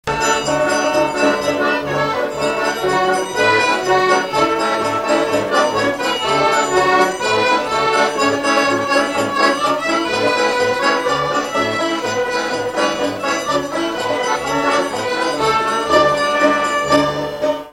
Instrumental
à marcher
Pièce musicale inédite